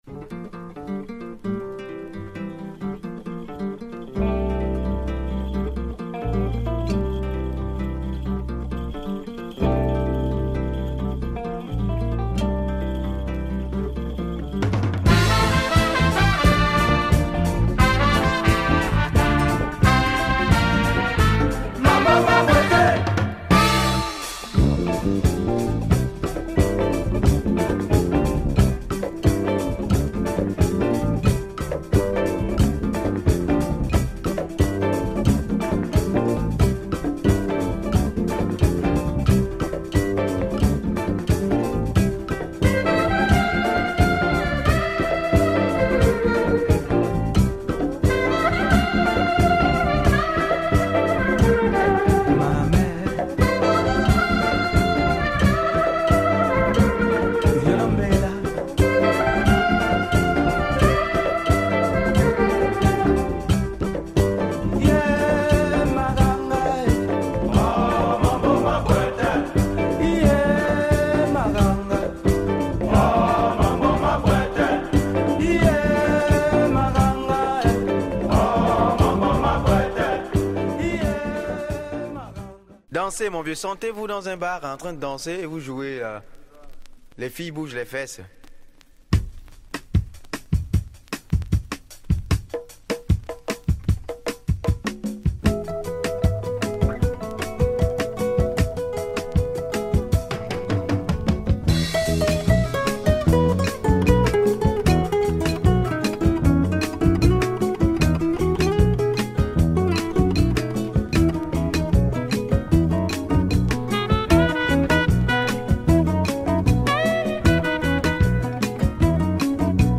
Excellent -and quite hard to find- afro music from Gabon